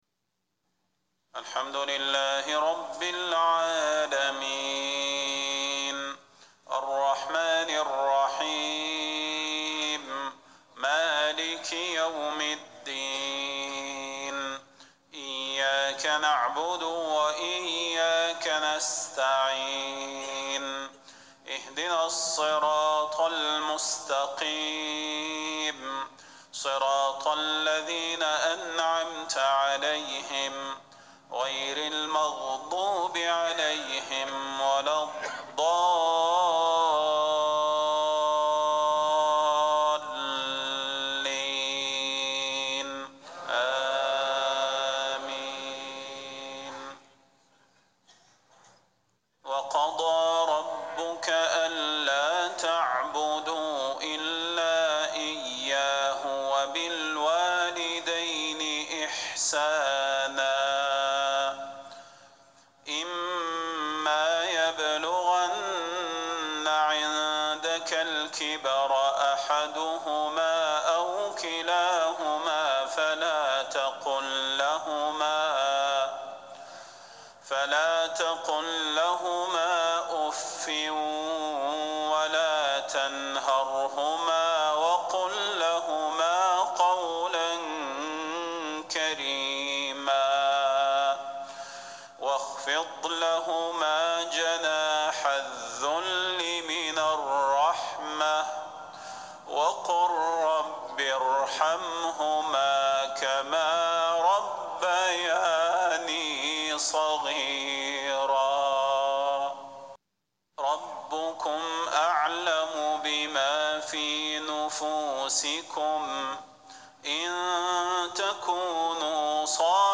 صلاة المغرب 1-5-1440هـ من سورة الإسراء | Maghreb 7-1-2019 prayer from Surah Al-Isra > 1440 🕌 > الفروض - تلاوات الحرمين